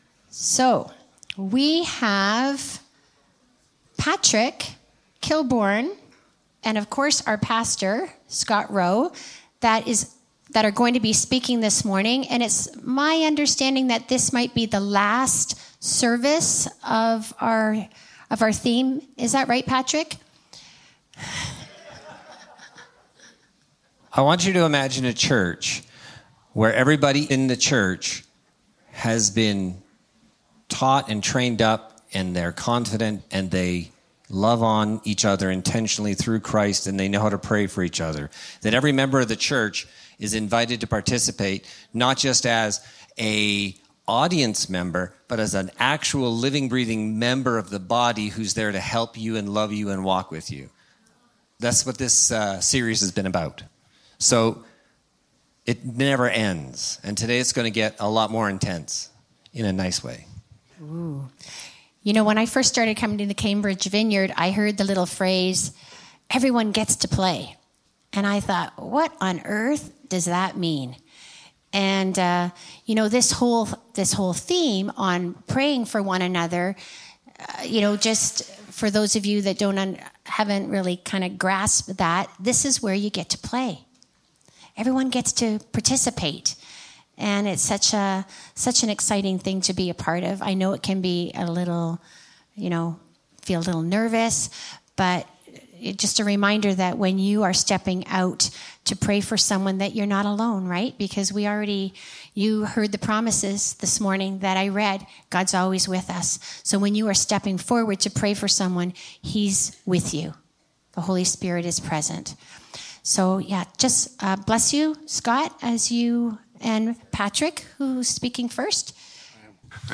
Passage: Luke 5: 17-26 Service Type: Sunday Morning Throughout the summer, we learned about prayer along with some demonstration and a bit of practice.